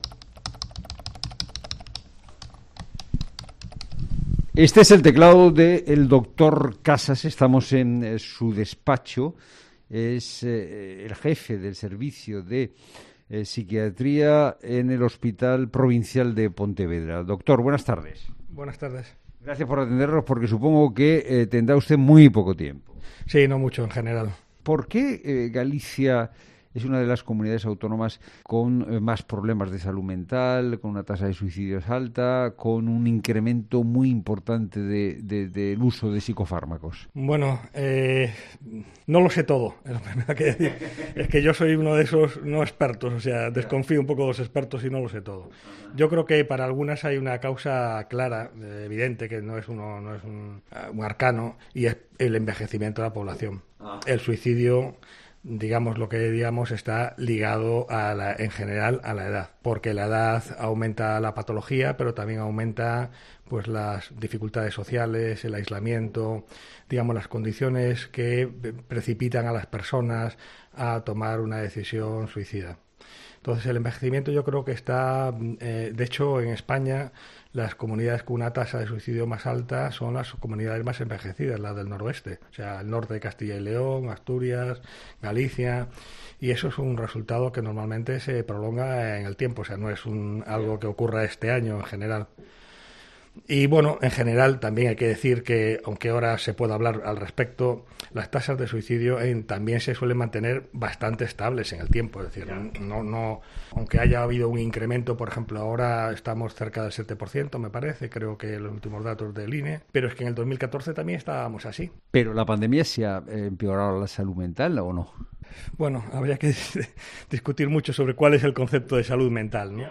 El experto explica a Fernando De Haro que guarda sus dudas sobre el término 'salud mental'.